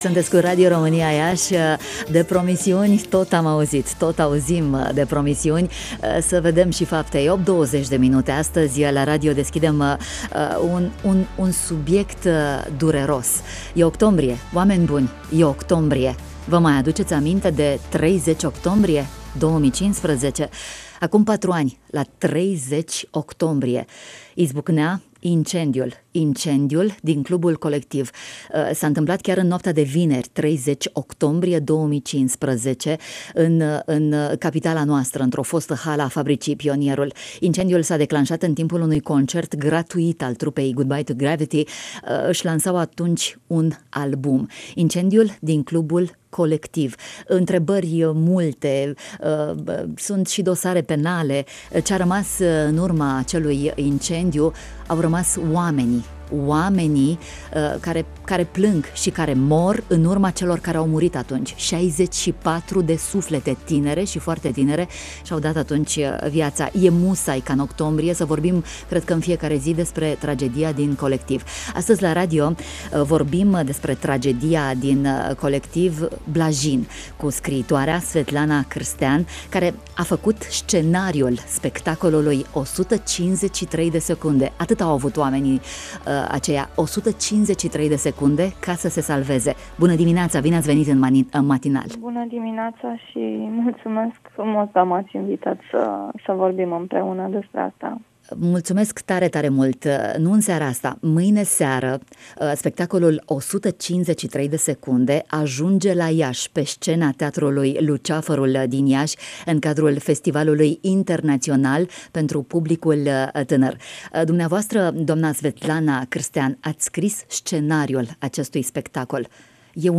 Aflaţi din dialogul de mai jos: